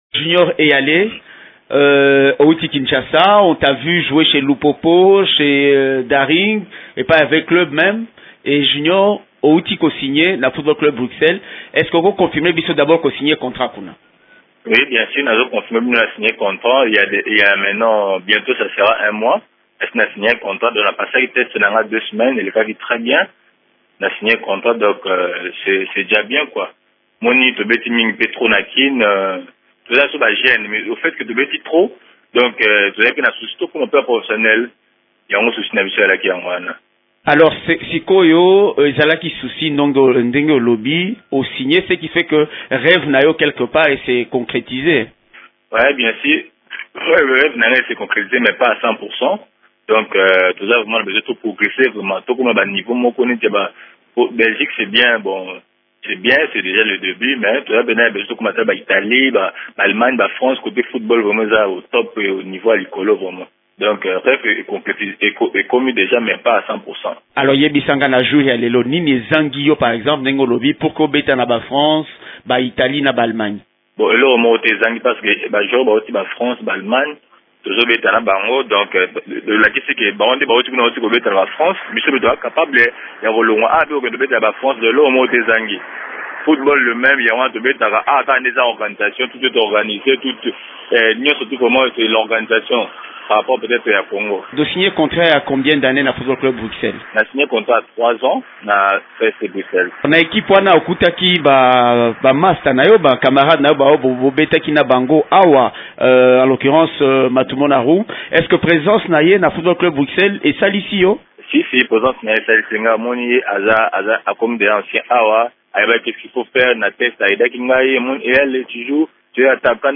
Il a été joint ce matin au téléphone